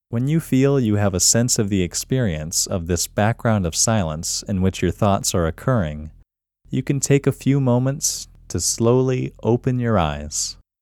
QUIETNESS Male English 15
The-Quietness-Technique-Male-English-15.mp3